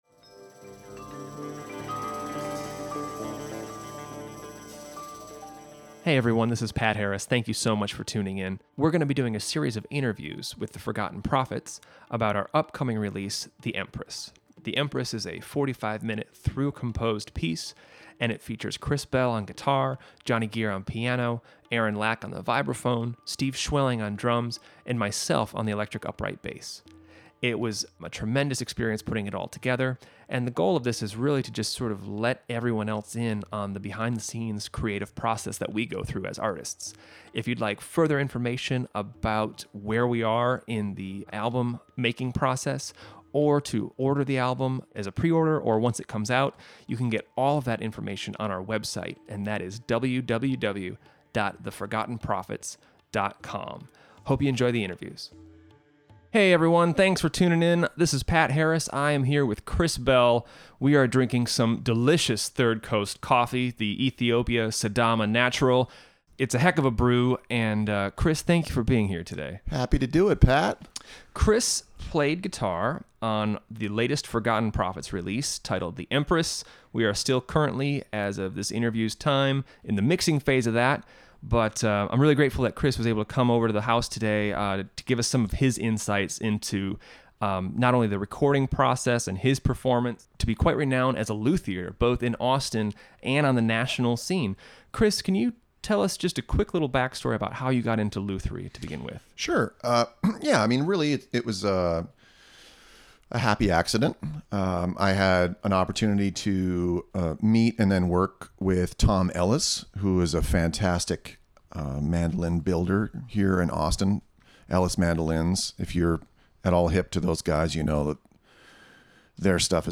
Part 1 in a series of interviews.